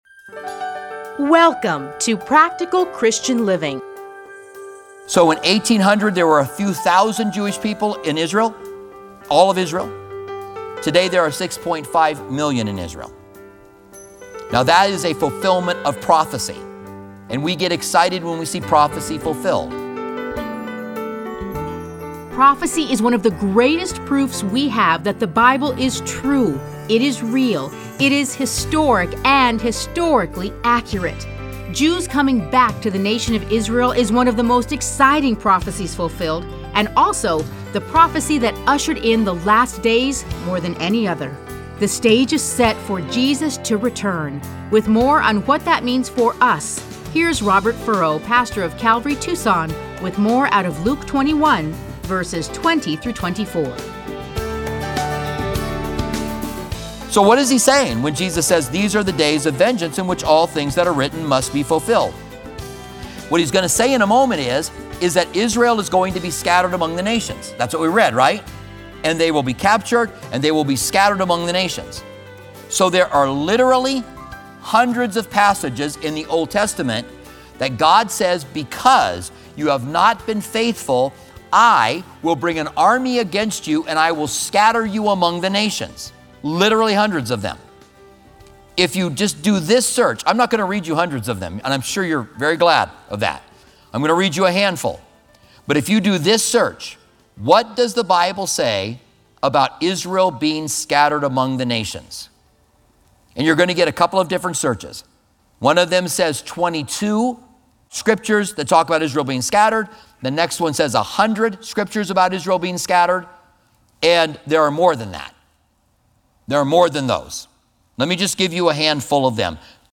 Listen to a teaching from Luke 21:20-24.